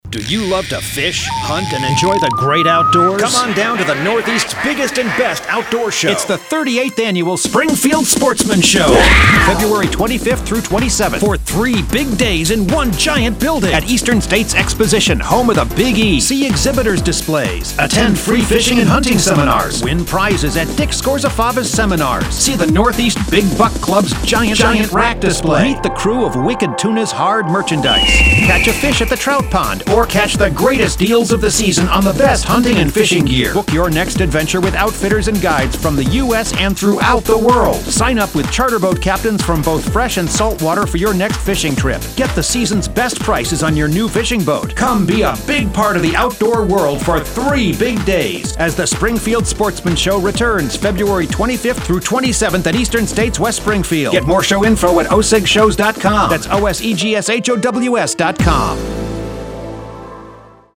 The 2022 Radio Ad